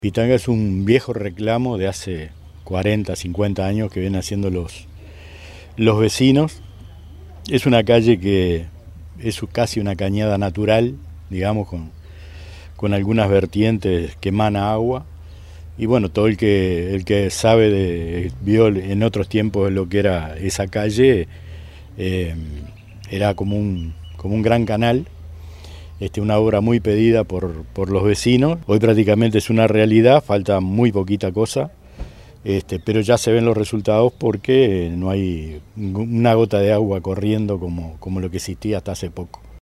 alcalde_de_salinas_oscar_montero_0.mp3